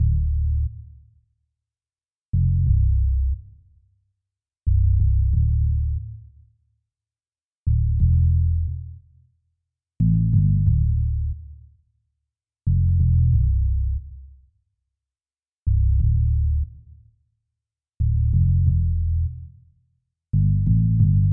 口琴(?)、贝斯、合成器乐曲、打击性合成器乐曲和其他。
Tag: 器乐 电子 合成器 循环 冷酷 钢琴